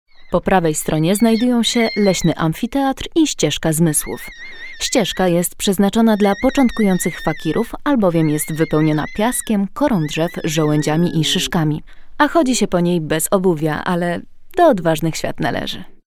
Female 30-50 lat
Nagranie lektorskie